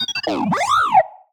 happy6.ogg